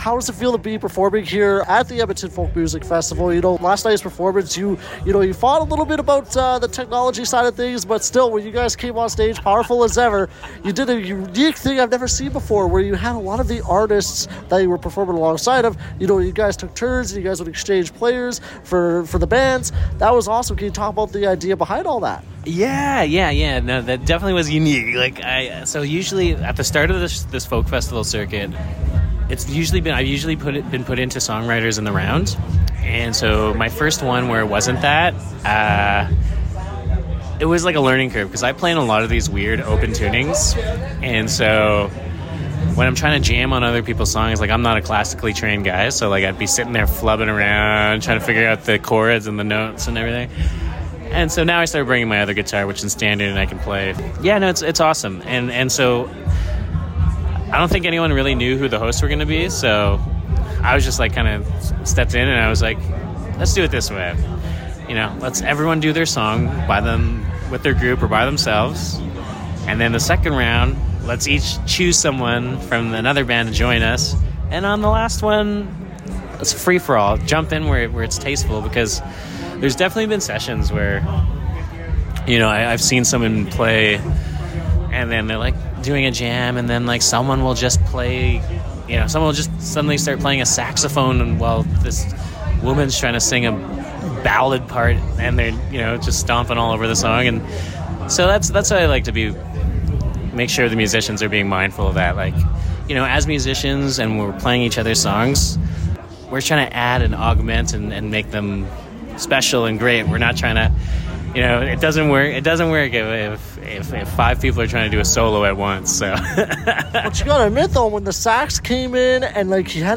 The singer-Songwriter from Sandy Lake Cree Nation in Ontario spoke with 89.3 the Raven